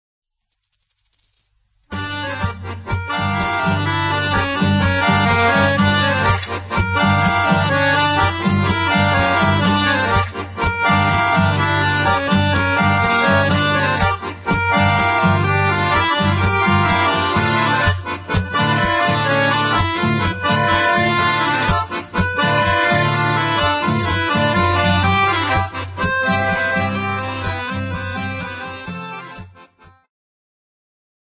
Segment Progressive
Acoustic
World Music
Healing